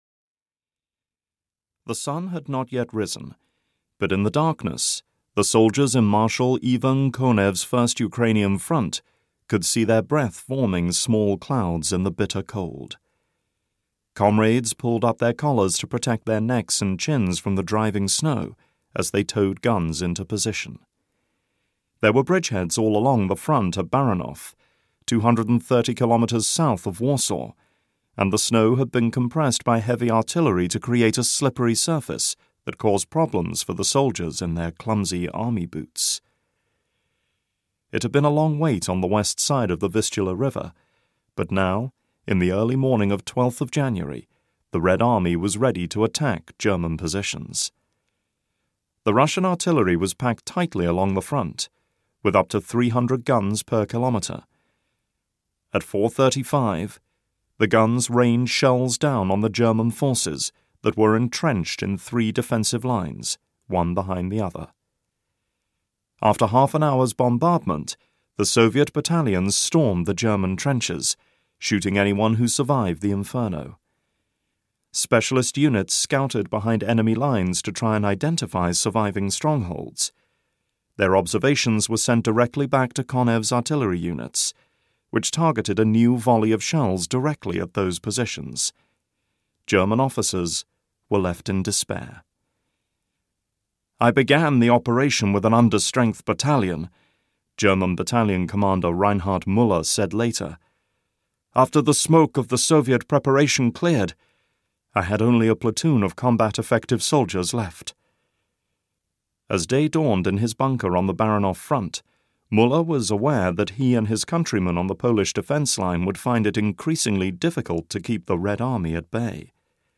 Fall of the Third Reich (EN) audiokniha
Ukázka z knihy